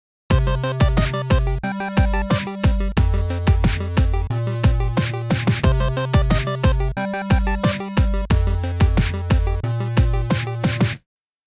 This one has a bum-note, and then everything stops!